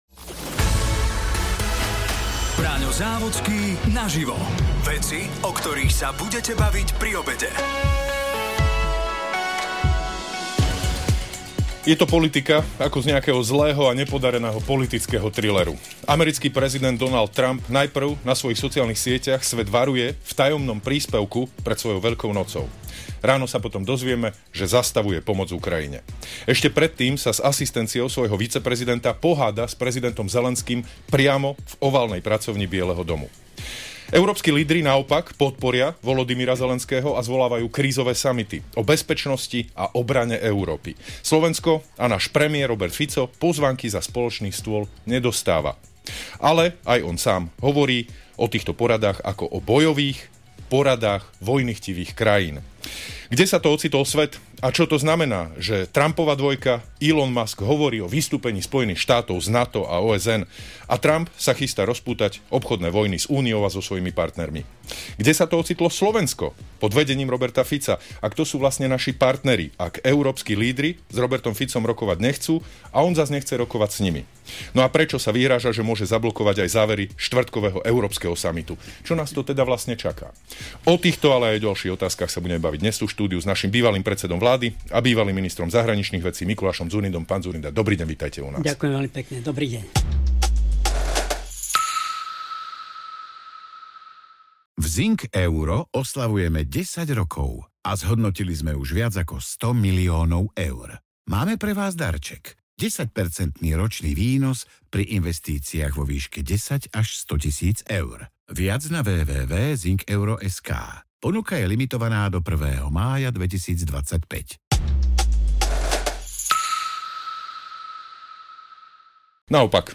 Braňo Závodský sa rozprával s bývalým premiérom a ministrom zahraničných vecí Mikulášom Dzurindom .